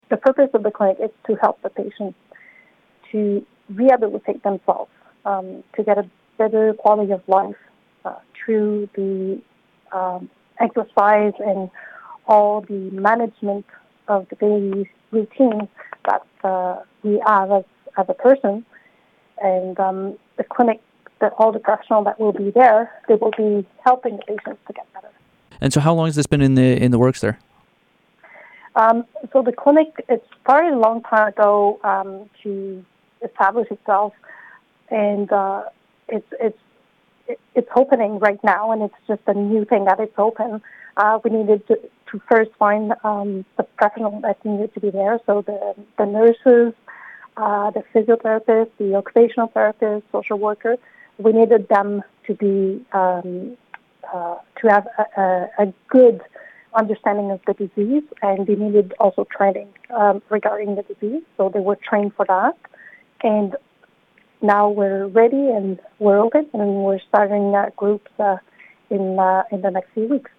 The full interview